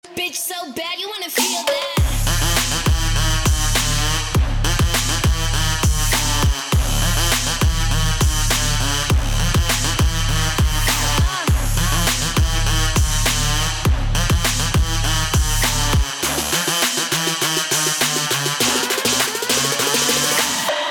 мощные басы
качающие